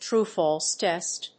アクセントtrúe‐fálse tèst